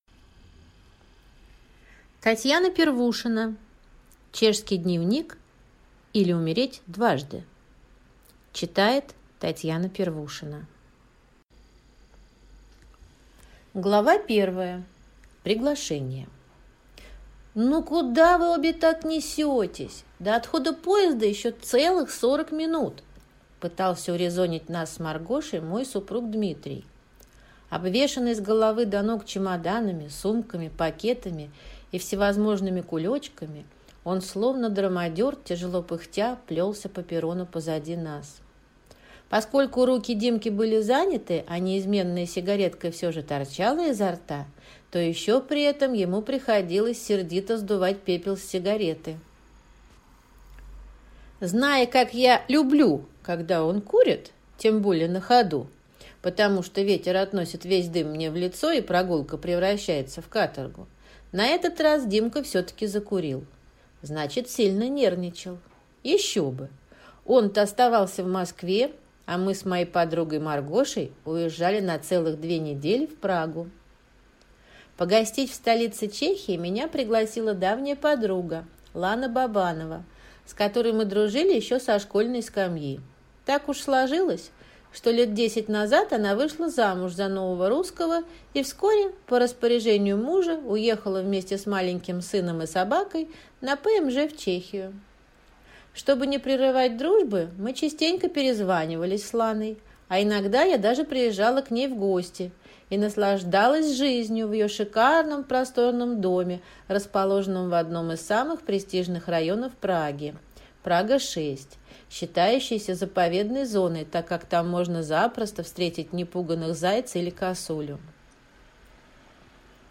Аудиокнига Умереть дважды | Библиотека аудиокниг